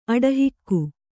♪ aḍahikku